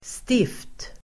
Uttal: [stif:t]